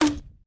sounds / guns / launcher.ogg
launcher.ogg